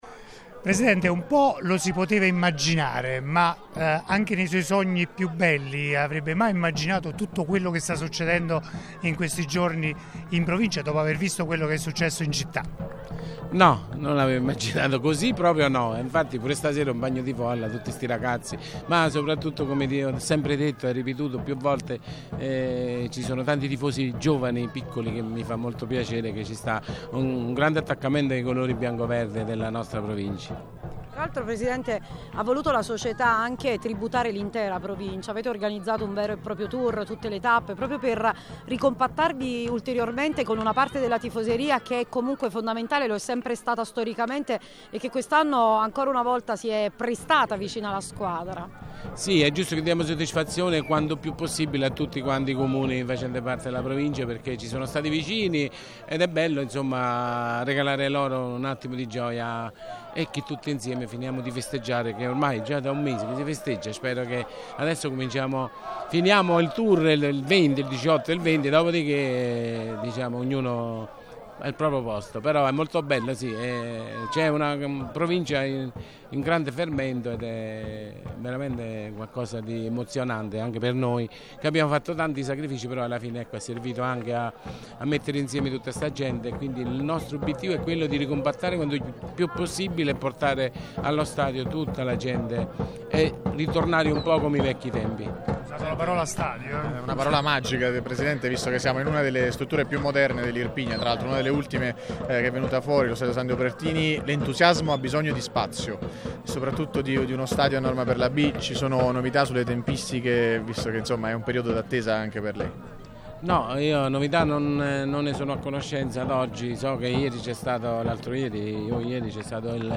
Occasioni ghiotta per incrociare microfoni e taccuini e restituire le ultime in merito allo Stadio Partenio Lombardi e sul futuro dei lupi in Serie B.